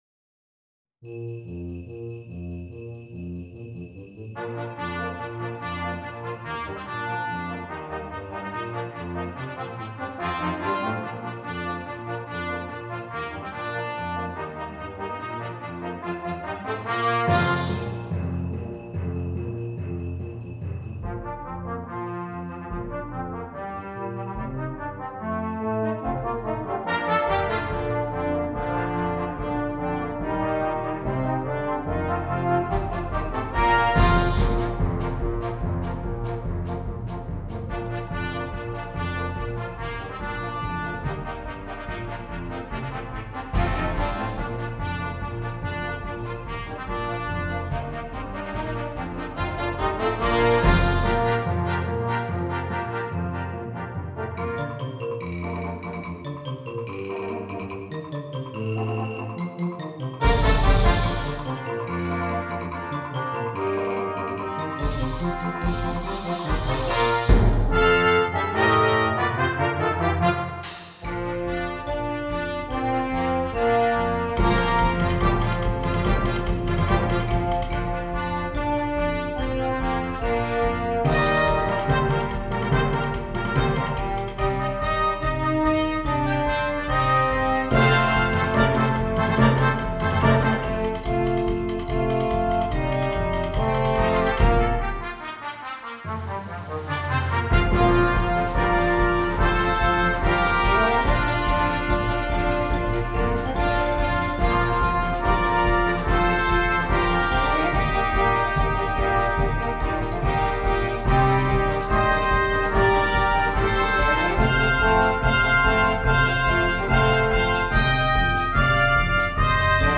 Composer: Traditional Carols
Voicing: 11 Brass w/ Percussion